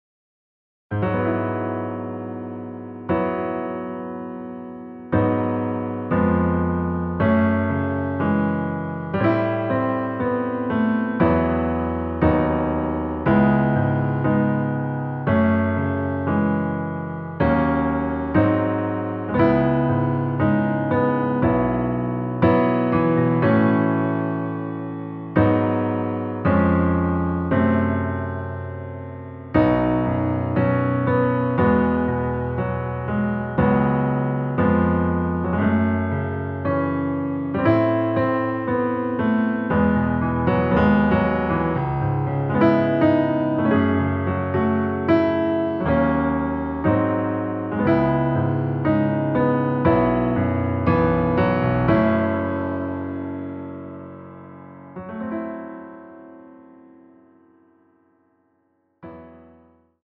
전주 없이 시작 하는곡이라 피아노 인트로 1마디 만들어 놓았습니다.
앞부분30초, 뒷부분30초씩 편집해서 올려 드리고 있습니다.